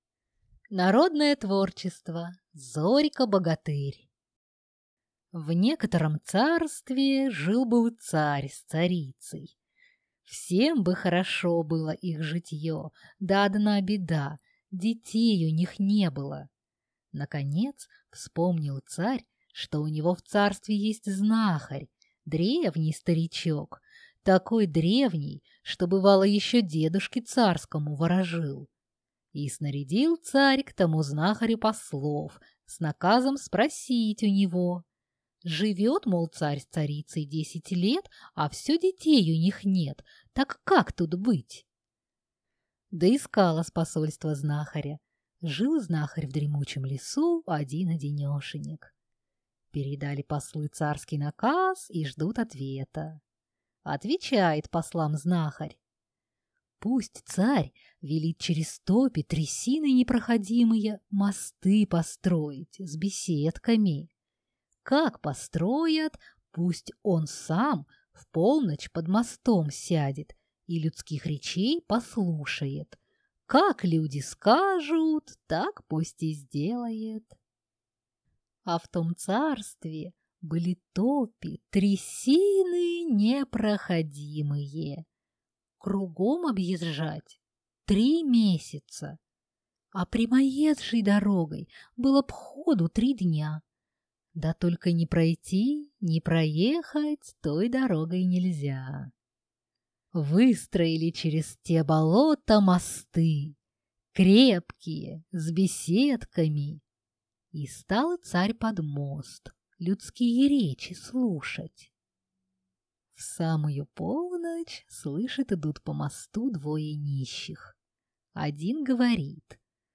Аудиокнига Зорька-богатырь | Библиотека аудиокниг